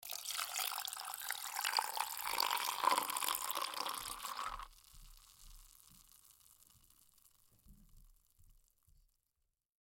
В коллекции представлены как натуральные записи, так и студийные варианты.
Сок - Альтернативный вариант